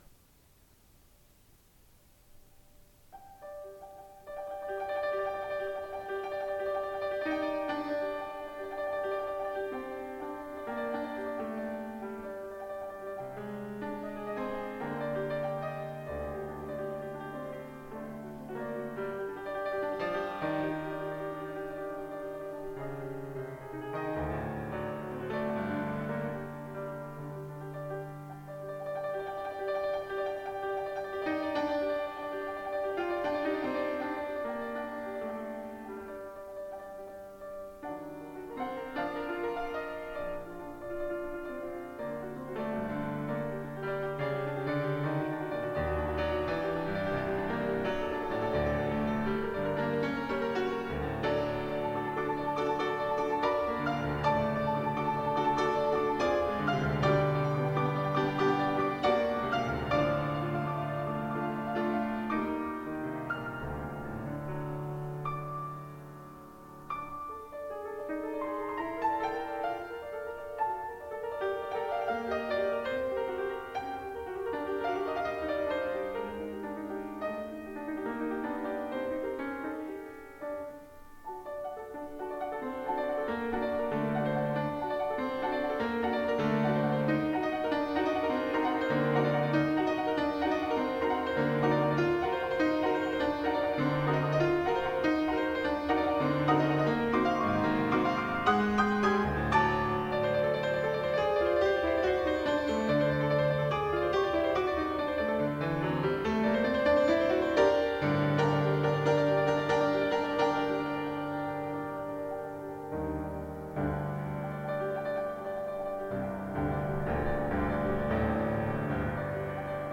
Recital - April 10, 1994